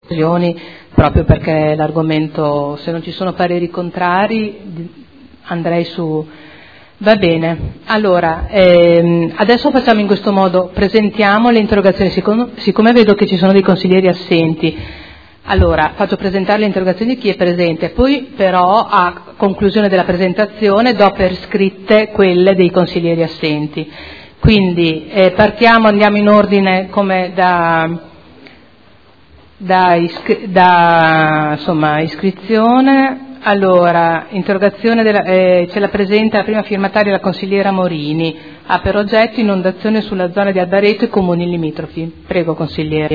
Seduta del 30/01/2014. Approva richiesta di prolungamento dei tempi d'intervento sulle interrogazioni riguardanti l'esondazione del fiume Secchia.